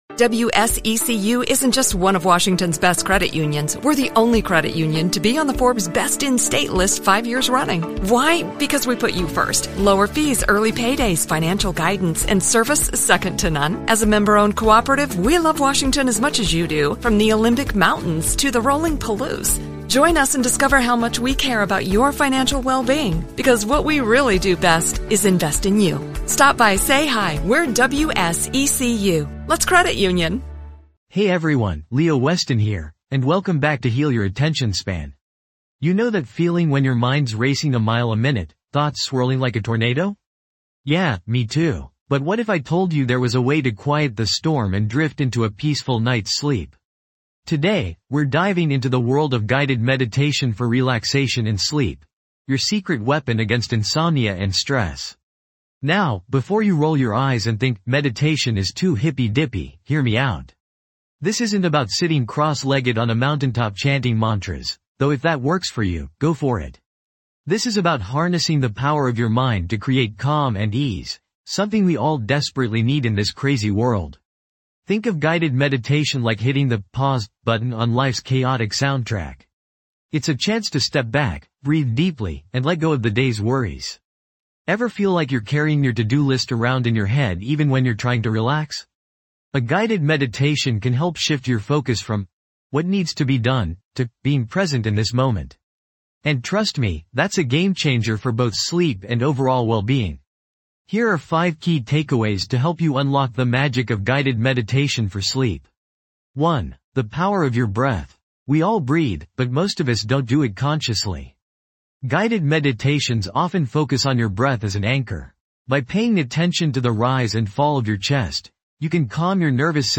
Deep Sleep & Relaxation Guided Meditation Daily Zzzs Boost
This podcast is created with the help of advanced AI to deliver thoughtful affirmations and positive messages just for you.